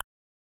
click3.mp3